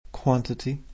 Irisch-Englisch